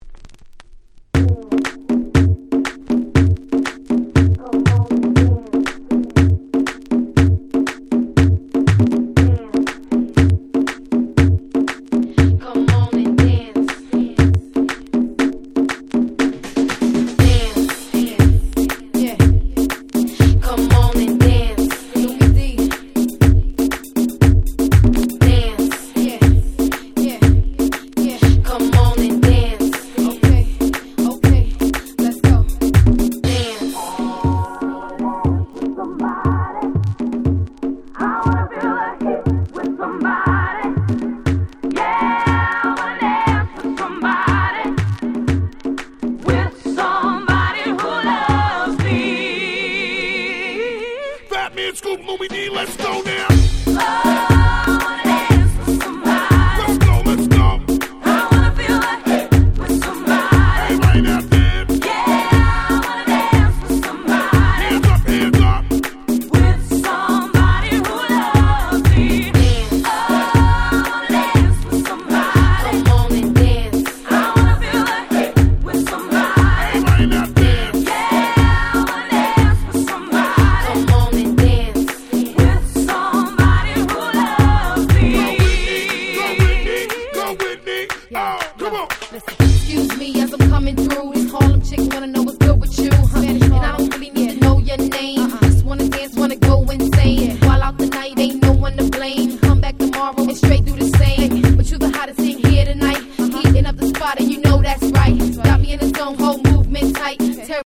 06' Nice Reggaeton !!